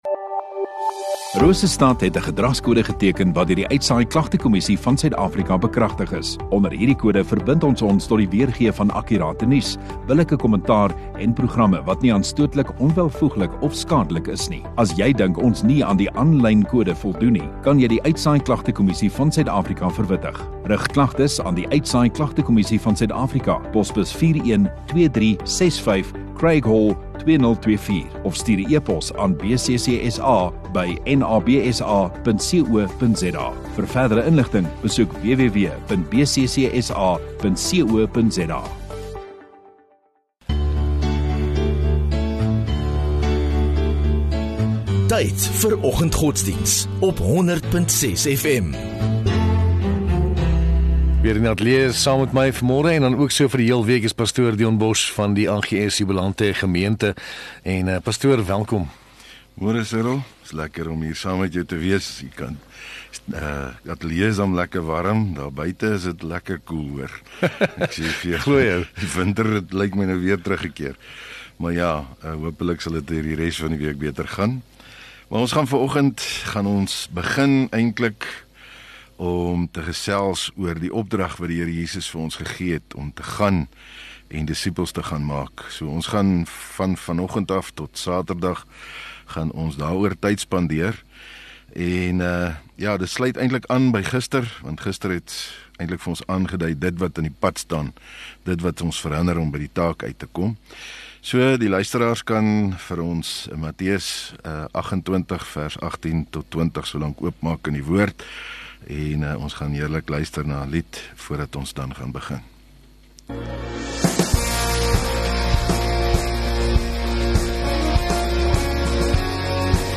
26 Sep Dinsdag Oggenddiens